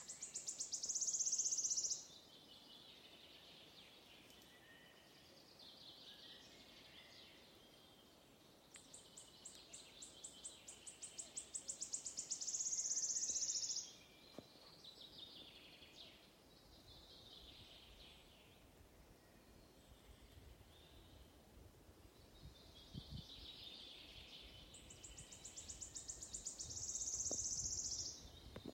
Птицы -> Славковые ->
пеночка-рещетка, Phylloscopus sibilatrix
СтатусПоёт